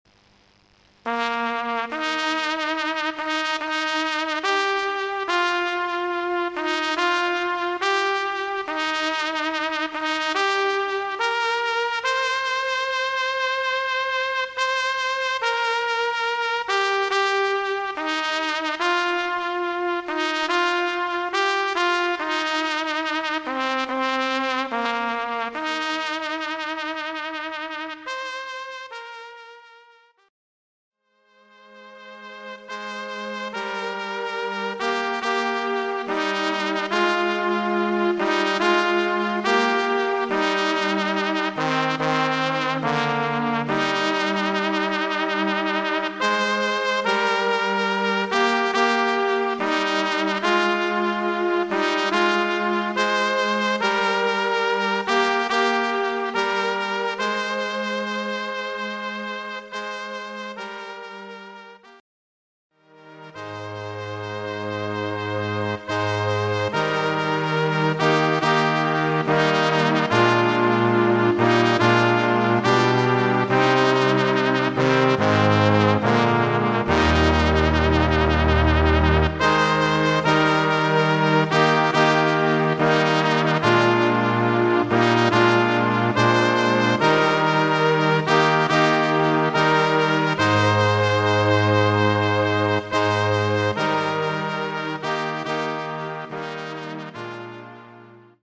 Trompete am Grab - *Trauermusik* Jessen - Lutherstadt Wittenberg - Annaburg